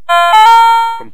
A sound clip of the Compy 386's startup noise.